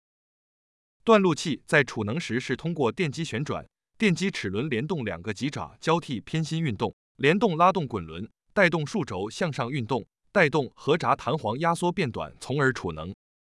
220断路器储能.mp3